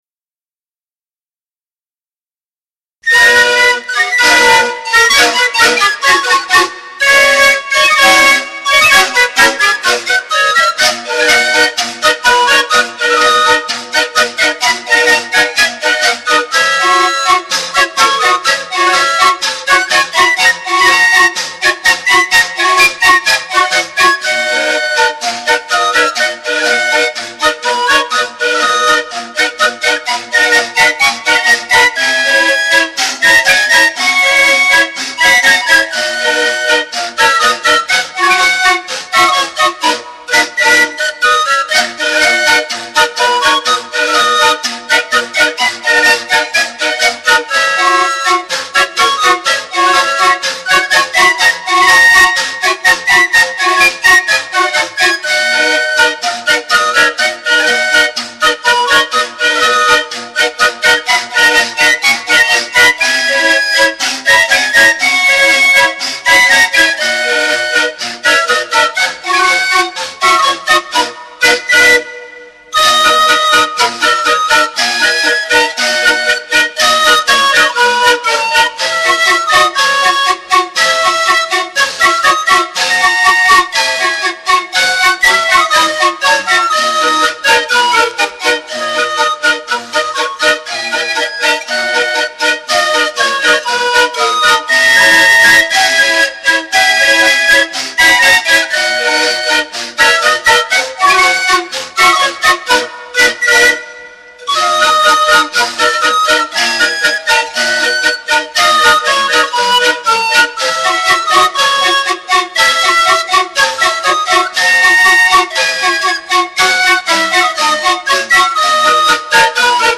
Marcia